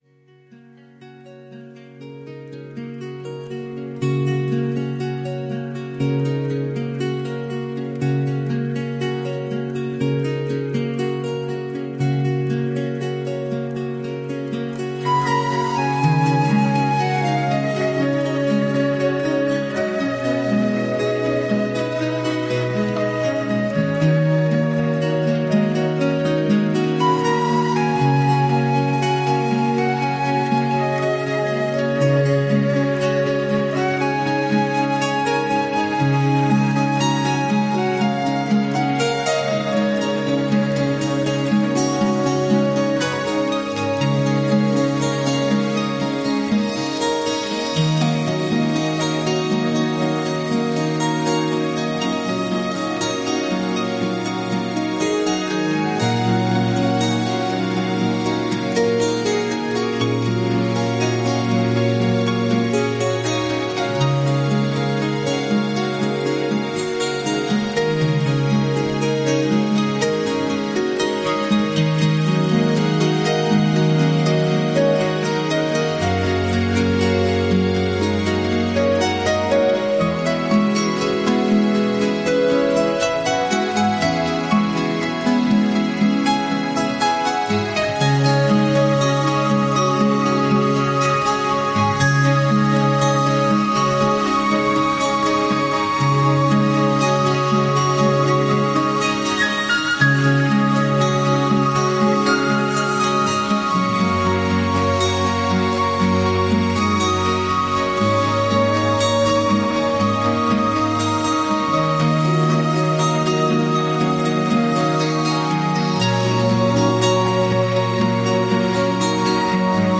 【説明】 この音楽は、静かで穏やかなアコースティックギターの旋律を中心に展開されます。